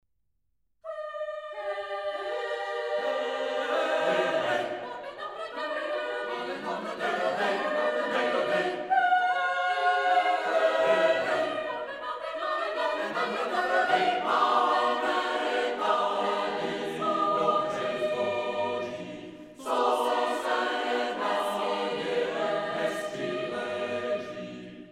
natočeno v červnu 1997 ve studiu Domovina v Praze
Česká sborová tvorba na texty lidové poezie